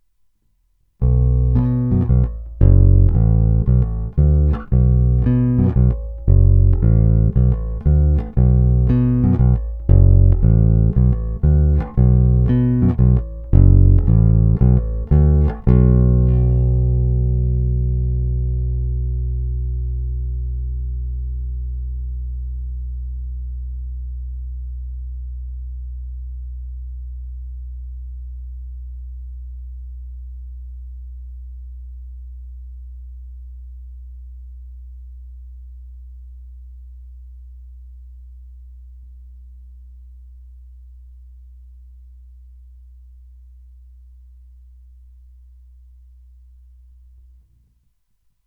Tohle má ten samý konkrétní, zvonivý a agresívní zvuk.
Následující zvukové ukázky jsou provedeny přímo do zvukové karty a kromě normalizace ponechány bez jakýchkoli úprav. Použité jsou neznámé niklové struny ze sady 45-105 ve slušném stavu. Tónová clona vždy plně otevřená.
Hráno mezi krkem a snímačem